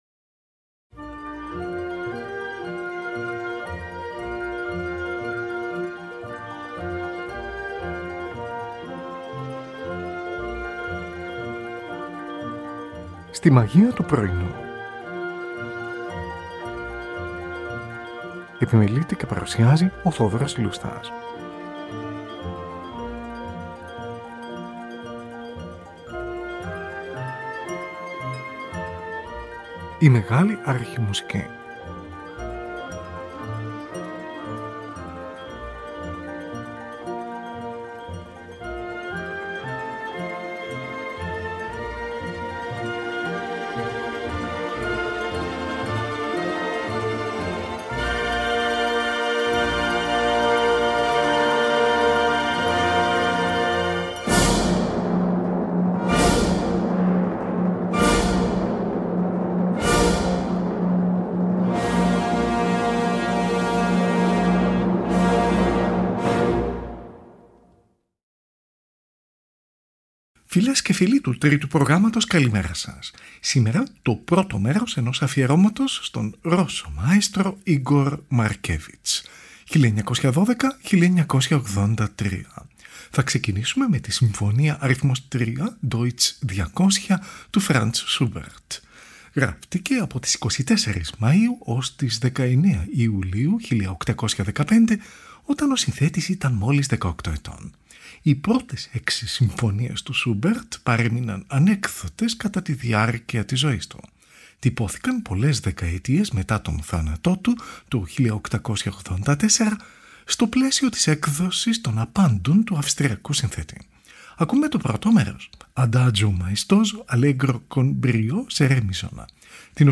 Nikolai Rimsky-Korsakov: Ορχηστρική Εισαγωγή της Όπερας «Νύκτα Μαΐου» ή «Η πνιγμένη κόρη».
Anatoly Liadov: Συμφωνικό Ποίημα “Kikimora”, έργο 63.